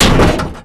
car_heavy_1.wav